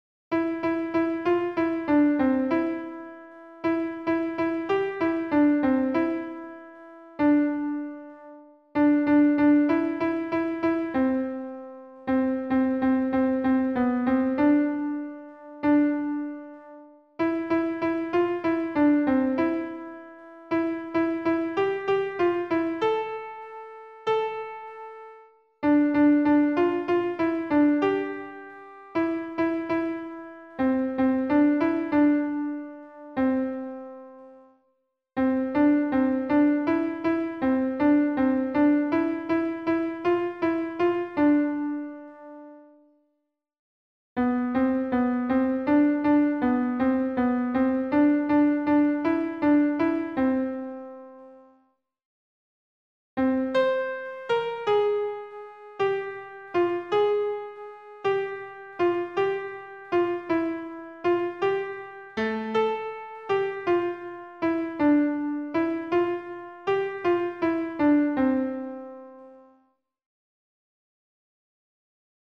piano) Une seule voix "alto"